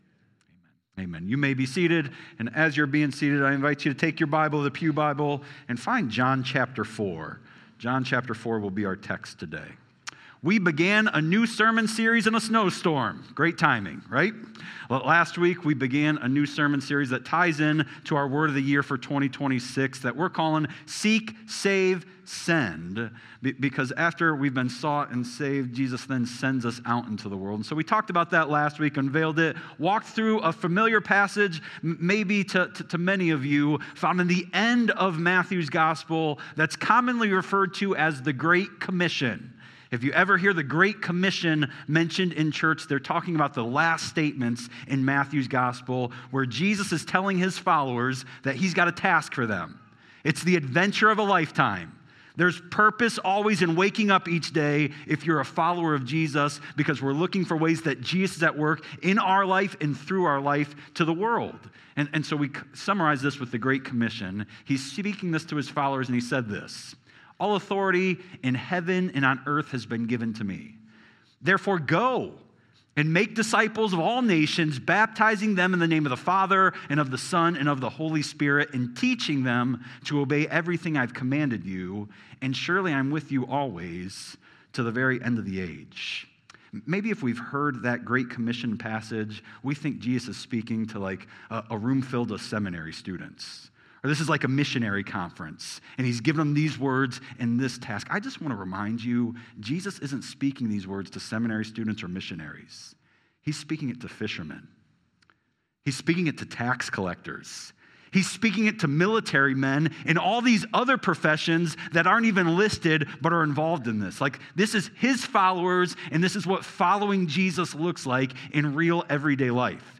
Sermons | First Church Bellevue